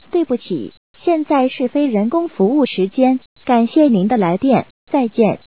假期提示音.wav